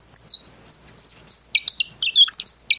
Metal Squeaks Loop